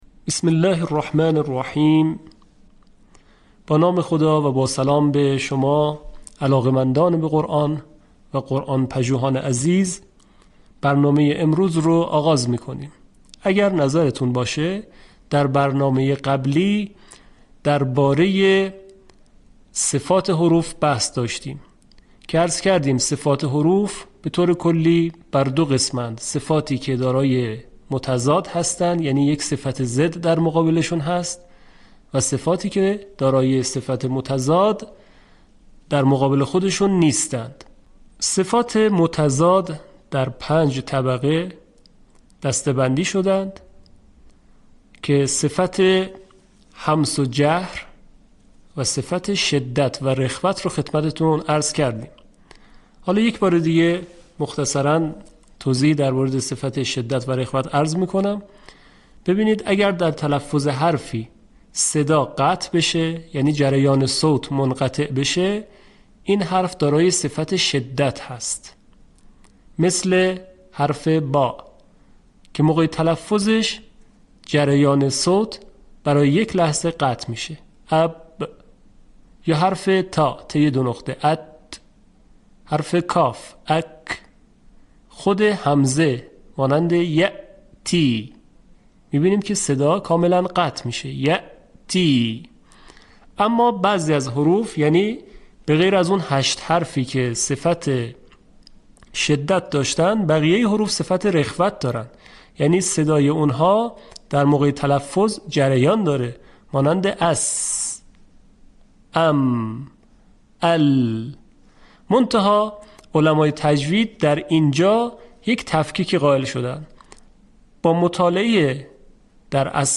به همین منظور مجموعه آموزشی شنیداری (صوتی) قرآنی را گردآوری و برای علاقه‌مندان بازنشر می‌کند.